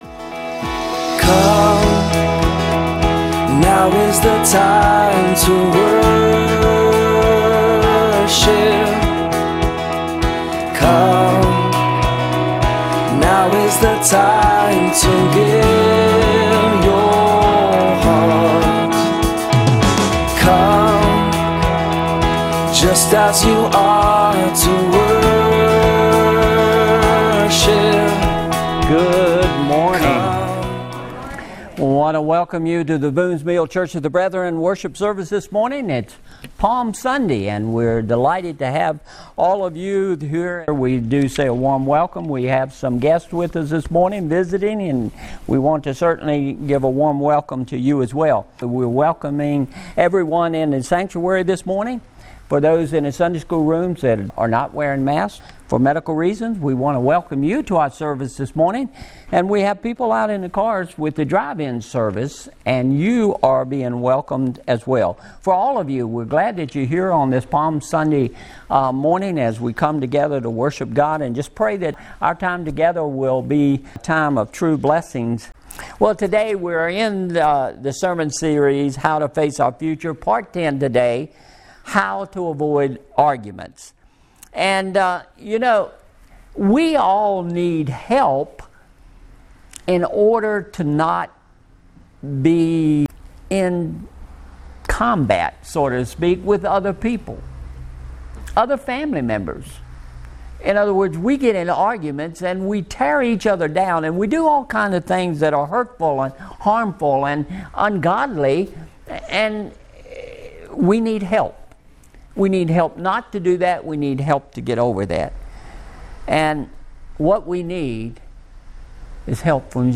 Mar 28, 2021 How to Avoid Arguments MP3 Notes Discussion Sermons in this Series Sermon Series How to Face Our Future!